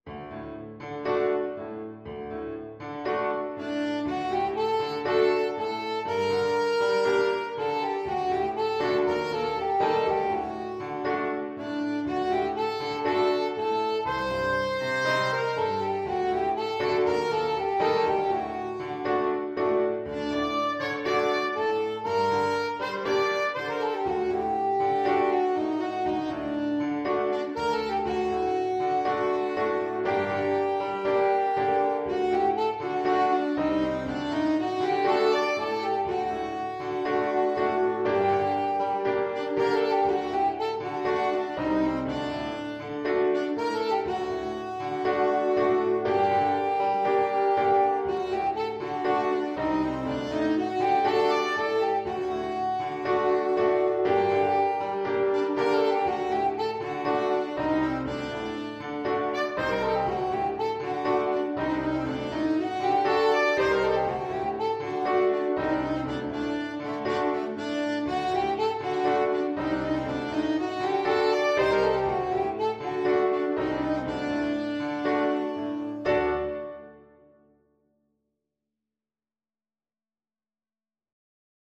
Traditional Trad. Araber Tanz (Klezmer) Alto Saxophone version
4/4 (View more 4/4 Music)
G minor (Sounding Pitch) E minor (Alto Saxophone in Eb) (View more G minor Music for Saxophone )
Allegro moderato =120 (View more music marked Allegro)
Traditional (View more Traditional Saxophone Music)
araber_tantz_ASAX.mp3